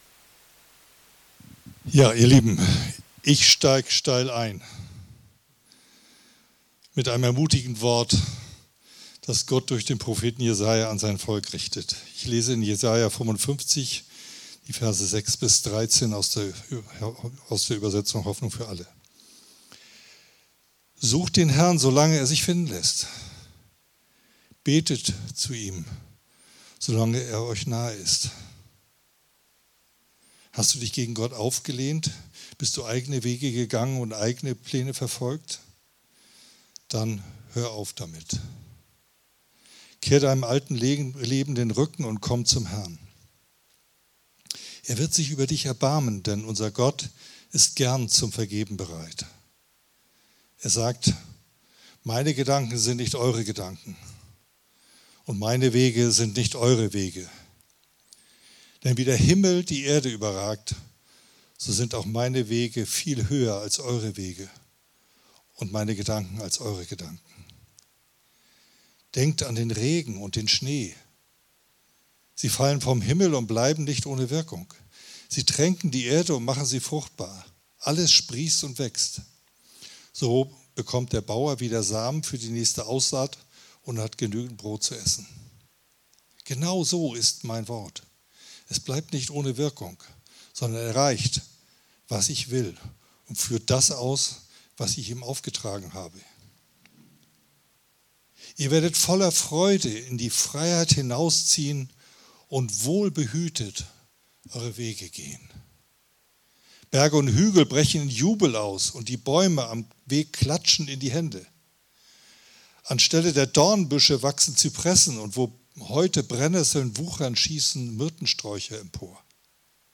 Predigt Gottesdienst: Sonntag